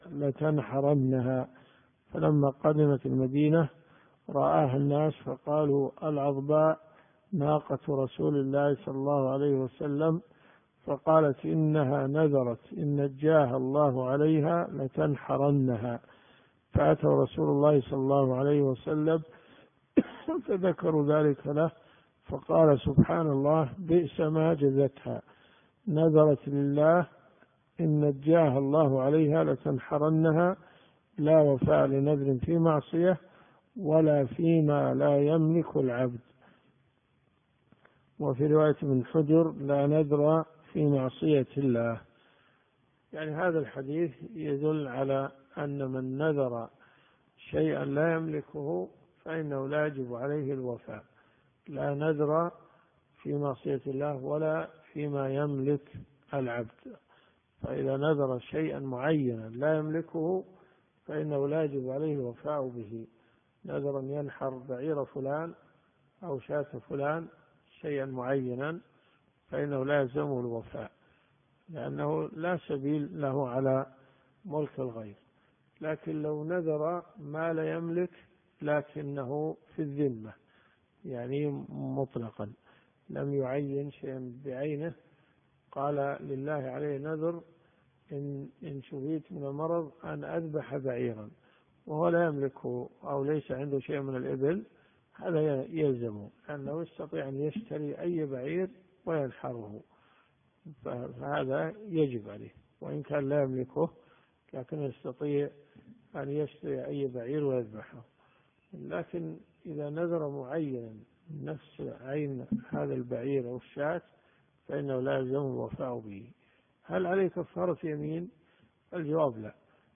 دروس صوتيه
صحيح مسلم . كتاب النذر . من حديث 4245 -إلى- حديث 4253 . اذا كان الصوت ضعيف استخدم سماعة الاذن